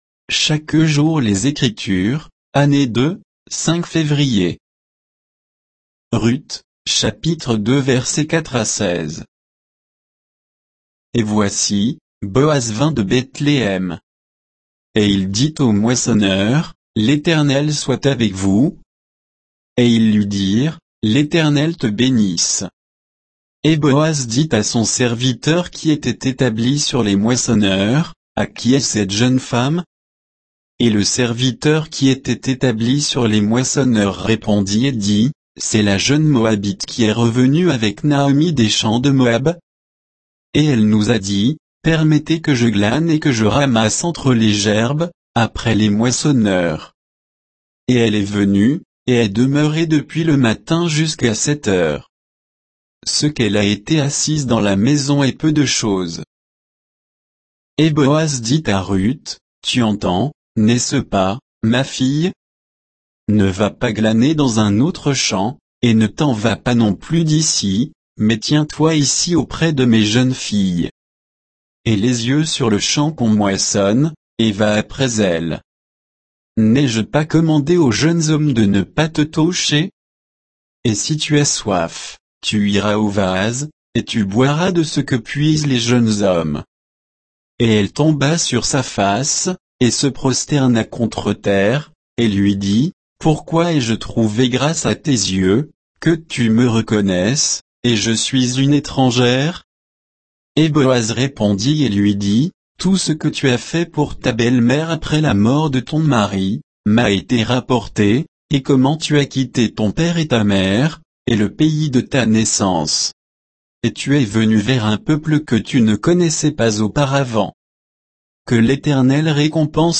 Méditation quoditienne de Chaque jour les Écritures sur Ruth 2